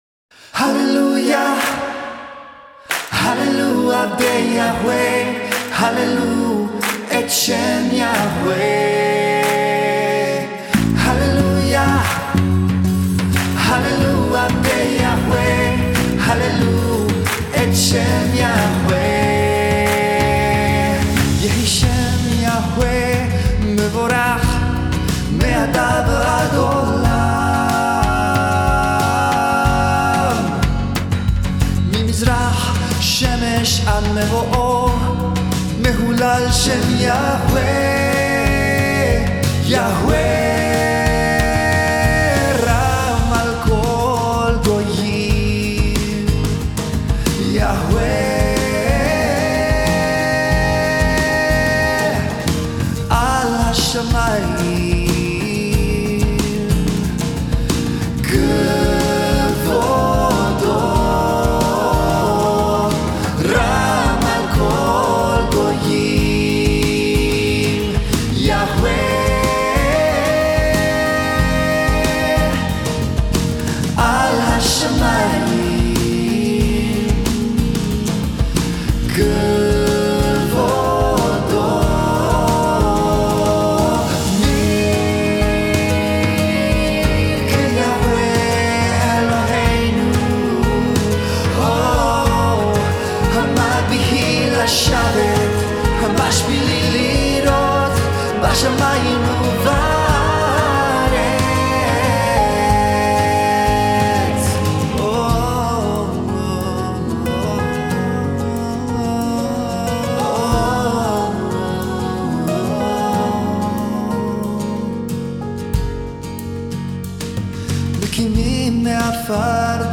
Piano
Bass
Female vocals